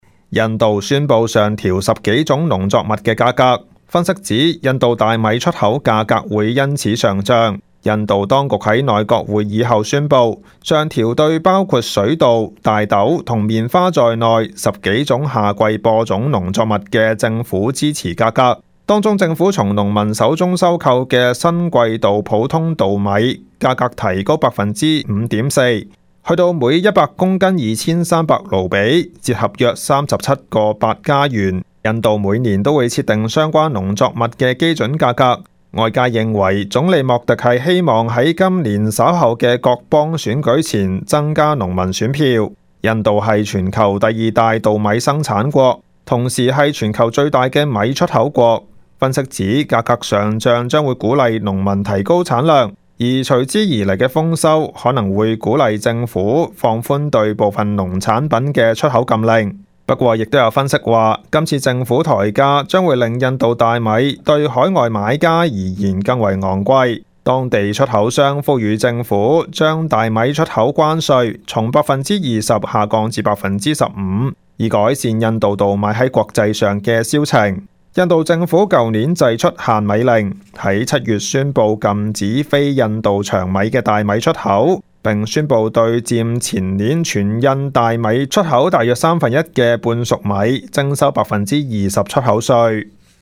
news_clip_19375.mp3